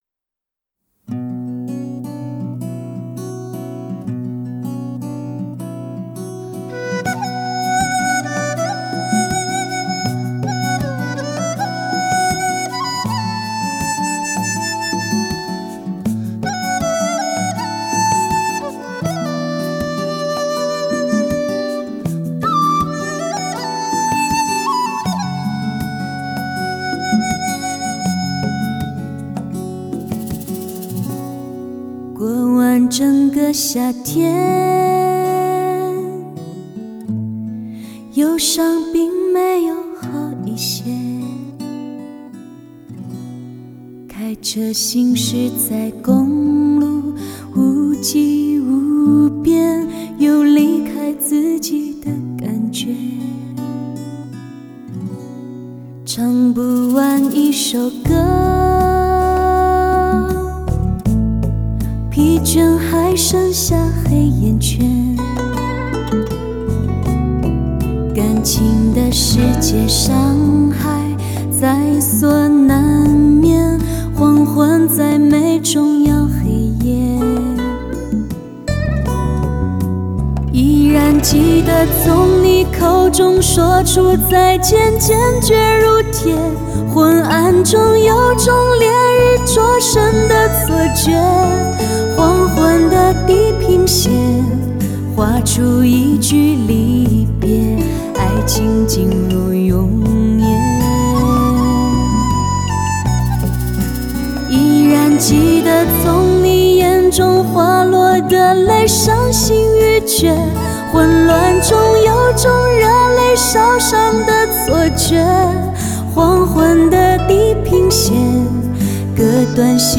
德国黑胶CD，兼容黑胶的高保真和CD的低噪音。